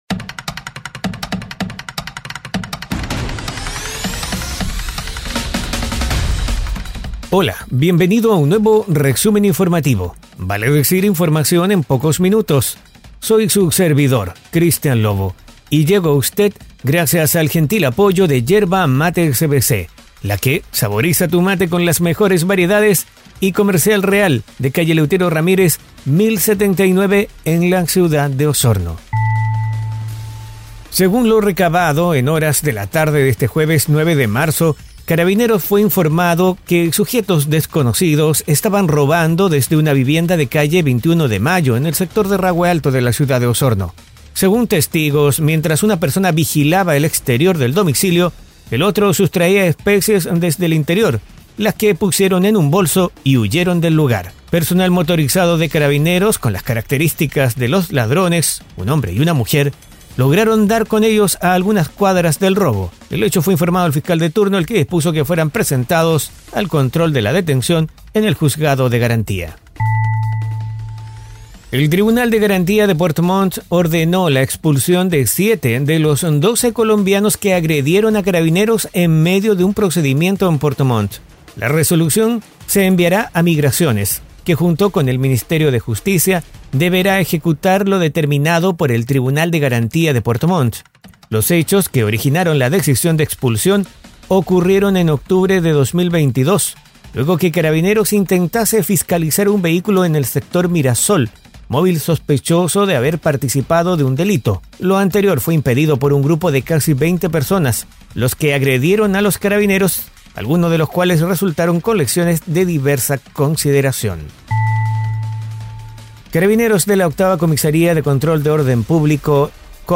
Resumen Informativo 🎙 Podcast 10 de marzo de 2023